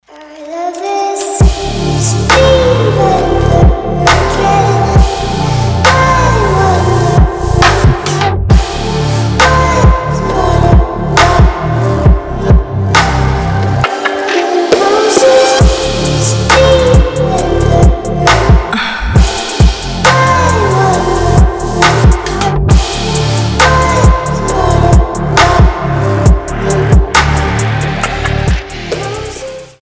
• Качество: 320, Stereo
атмосферные
спокойные
Chill Trap
Приятный чилловый ремикс